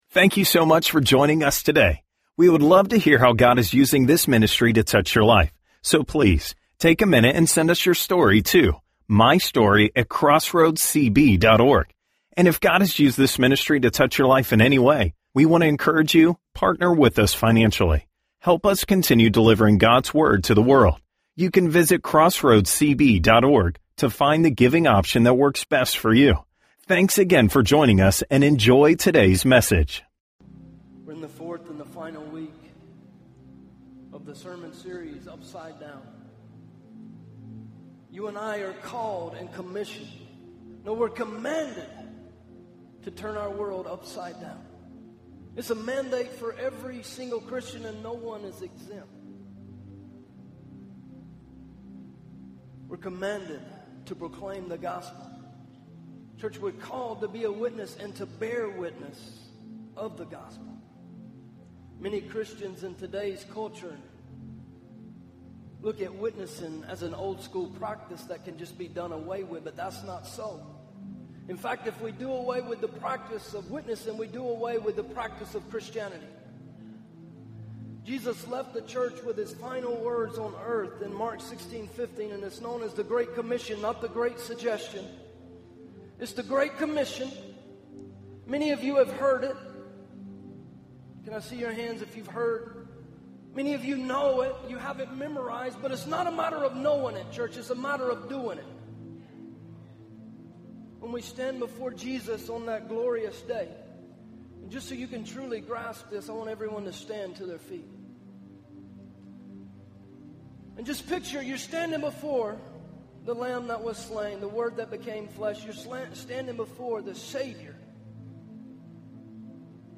Sermons - Crossroads Church